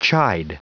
Prononciation du mot chide en anglais (fichier audio)
Prononciation du mot : chide